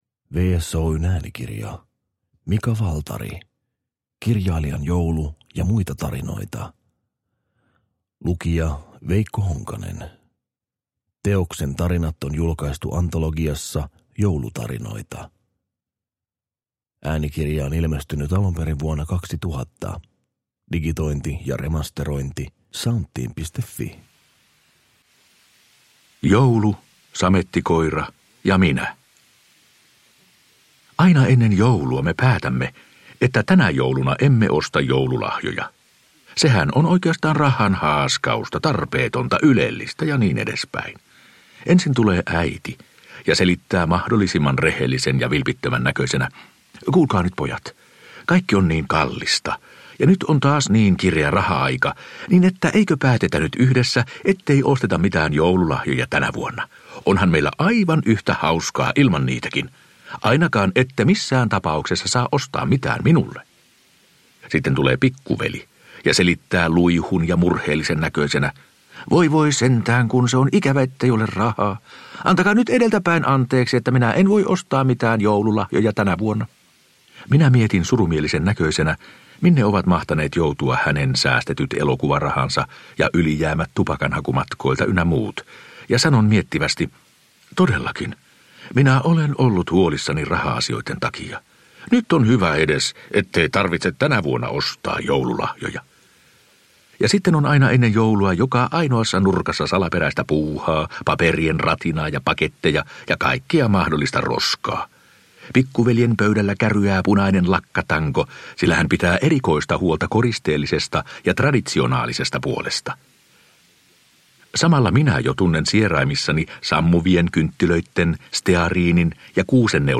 Äänikirja ilmestyi alun perin kasettikirjana.